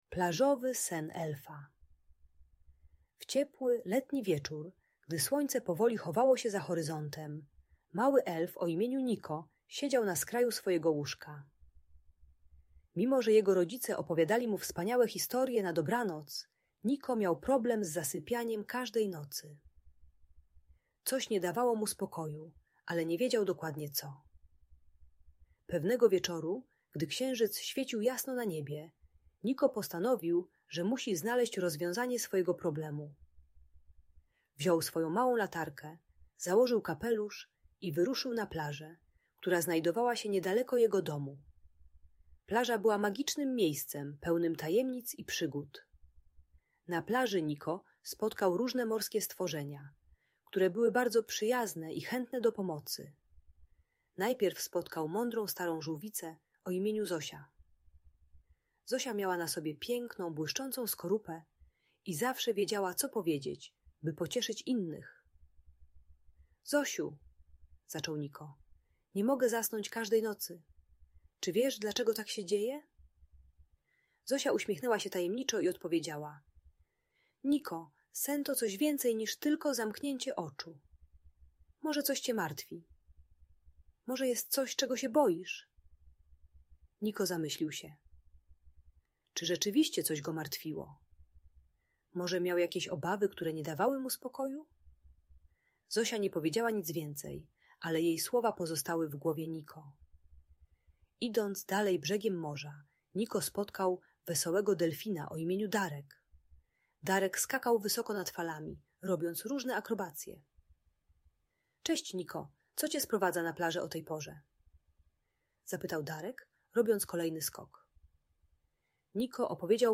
Plażowy Sen Elfa - Magiczna Historia na Dobranoc - Audiobajka